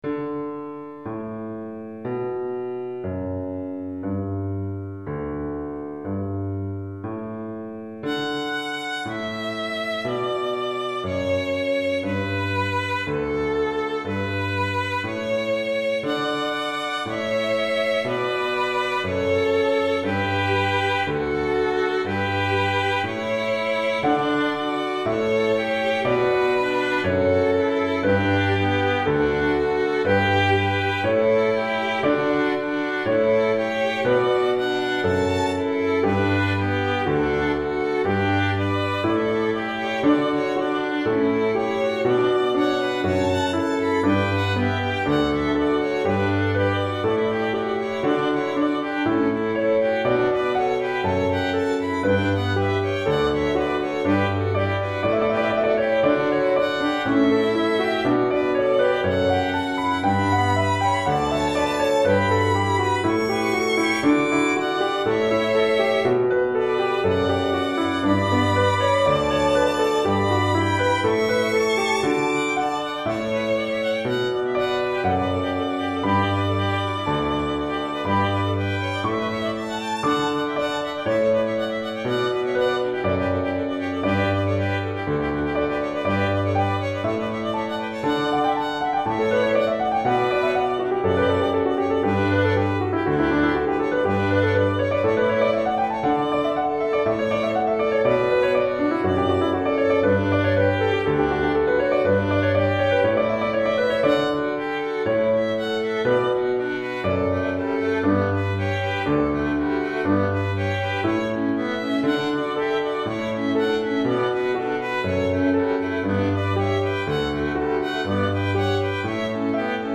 Répertoire pour Violon - 2 Violons et Piano